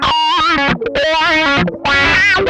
MANIC WAH 5.wav